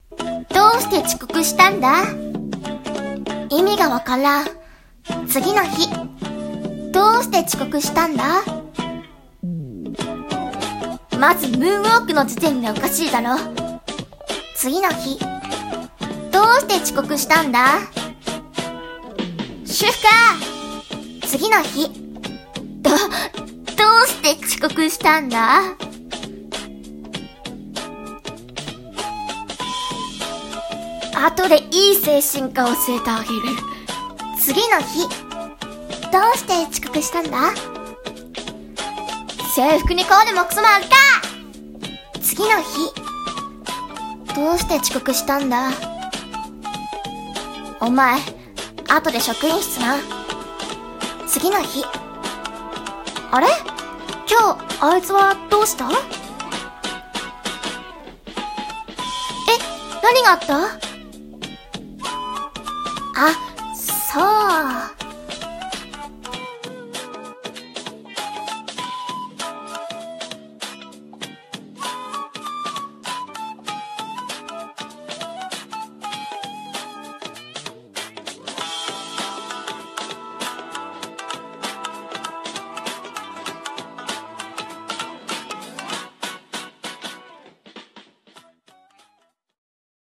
声劇「遅刻の理由」